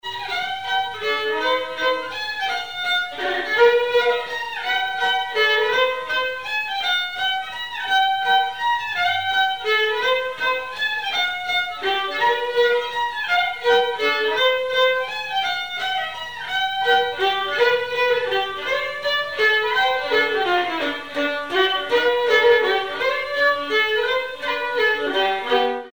Mazurka
danse : mazurka
circonstance : bal, dancerie
Pièce musicale inédite